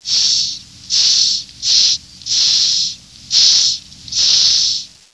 The Madagascar hissing cockroach is a gentle creature, all
Its alarm hiss is the cockroach’s attempt to scare off intruders.
hiss.wav